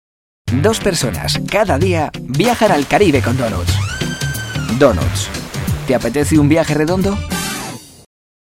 Sprecher spanisch für Hörfunk und Werbung
Sprechprobe: Industrie (Muttersprache):